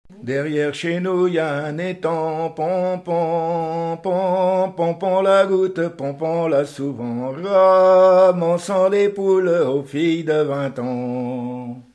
chant de conscrit
gestuel : à marcher
Genre laisse
Pièce musicale inédite